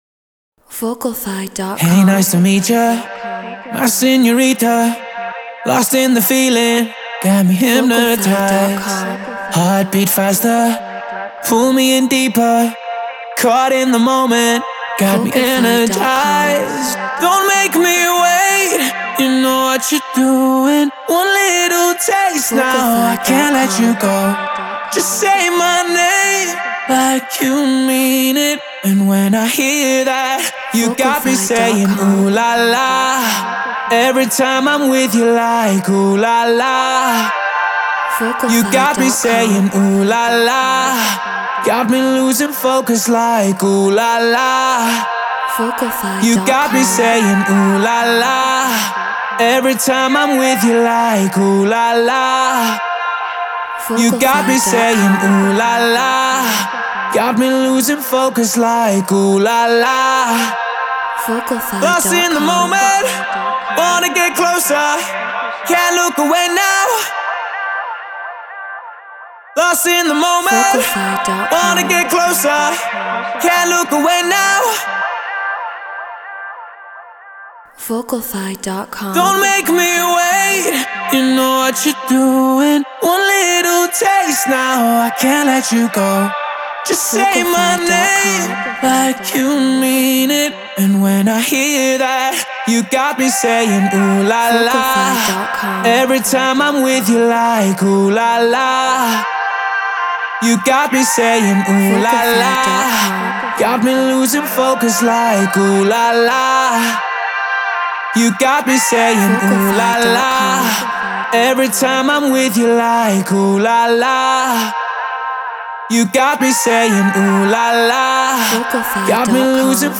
House 124 BPM Gmin
Neumann TLM 103 Apollo Twin X Pro Tools Treated Room